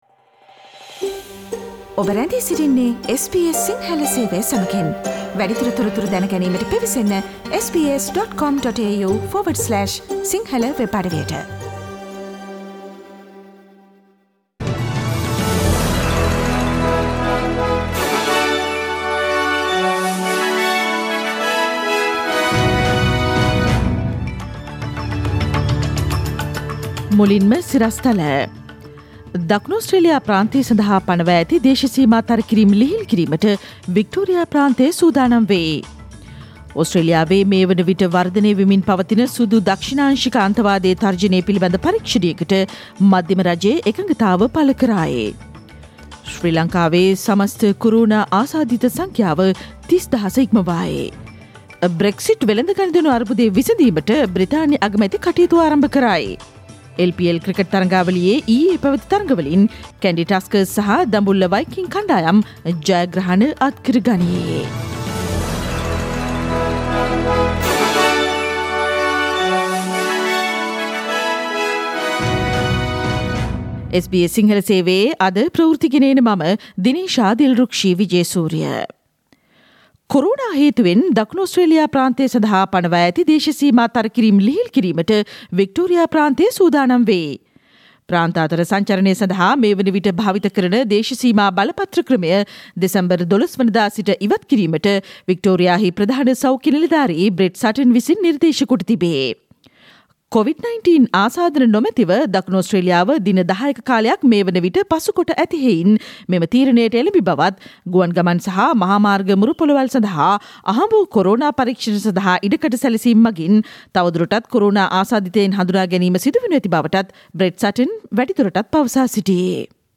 Today’s news bulletin of SBS Sinhala radio – Thursday 10 December 2020